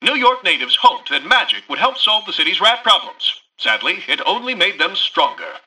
Newscaster_headline_49.mp3